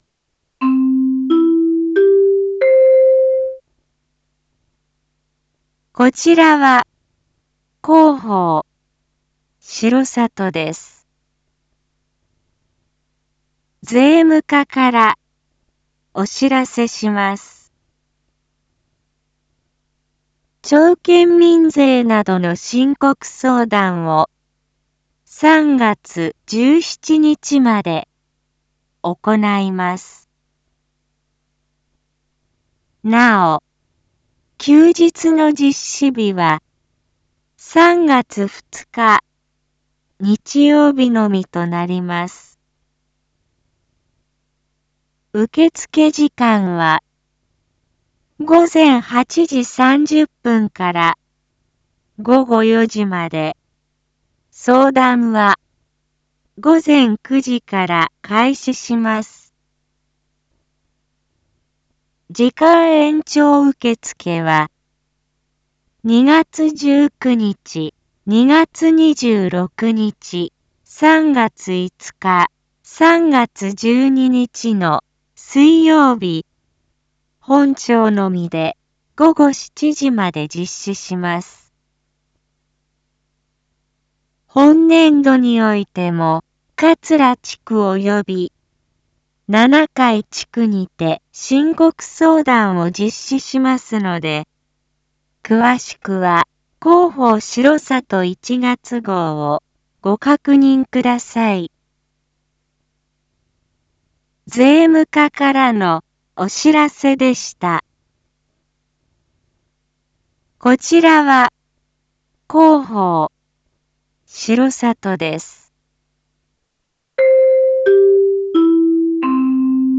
Back Home 一般放送情報 音声放送 再生 一般放送情報 登録日時：2025-02-17 07:02:02 タイトル：申告相談① インフォメーション：こちらは広報しろさとです。